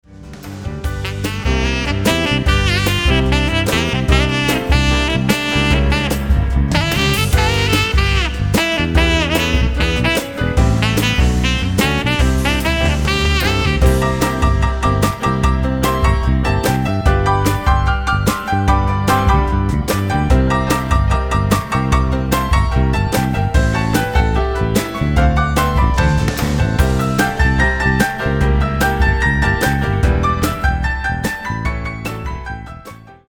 148 BPM